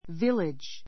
village 中 A2 vílidʒ ヴィ れ ヂ 名詞 複 villages vílidʒiz ヴィ れヂェ ズ ❶ 村 , 村落 ⦣ town （町）よりも小さなもの. a fishing village a fishing village 漁村 a village church a village church 村の教会 I lived in a little mountain village.